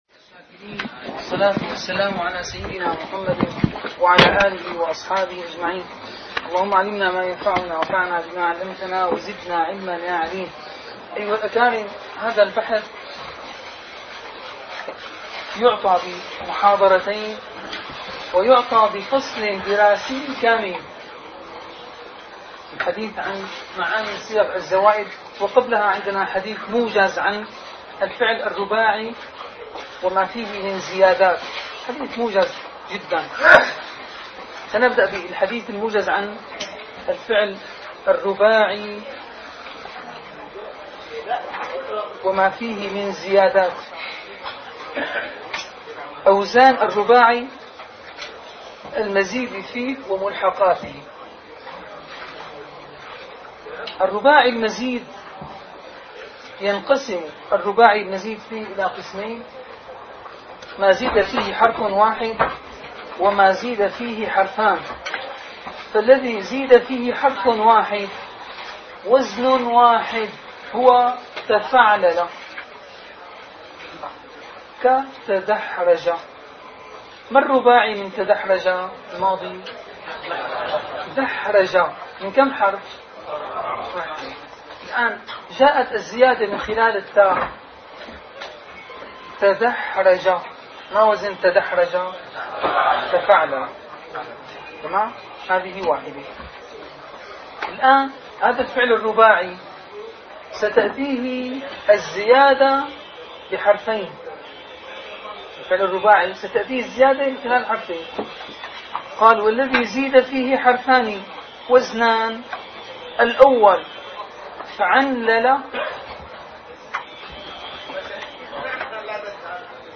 - الدروس العلمية - دورة مفصلة في النحو والإعراب والصرف - الإعراب - الدرس العشرون